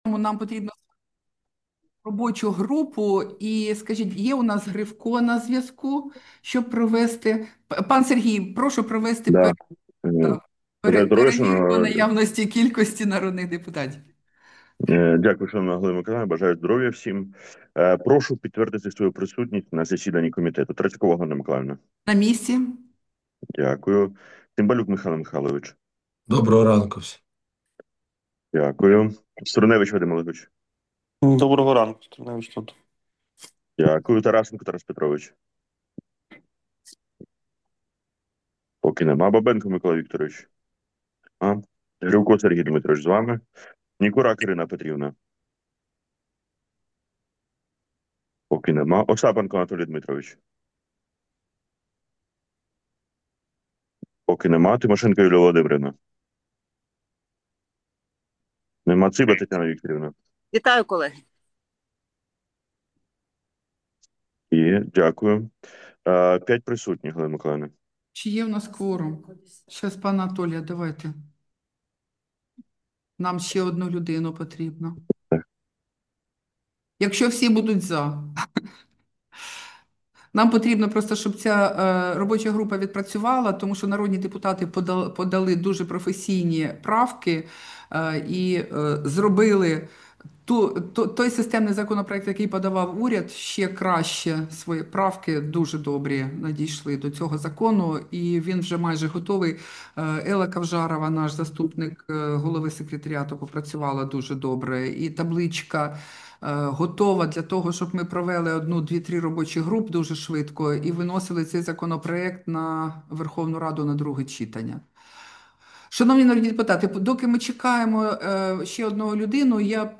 Засідання Комітету від 12 вересня 2025 року